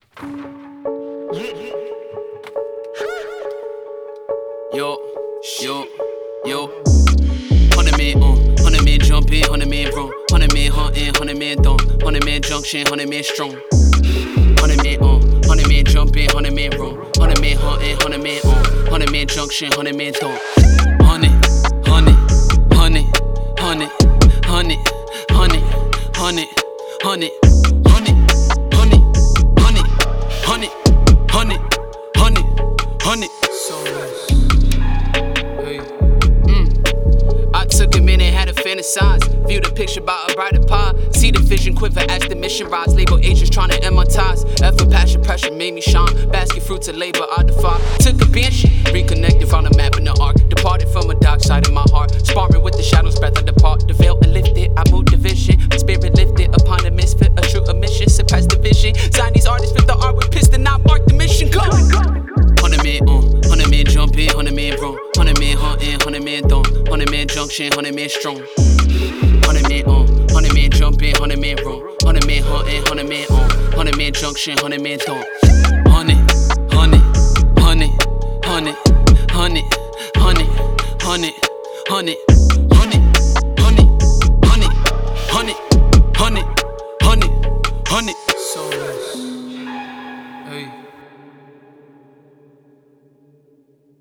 hard, aggressive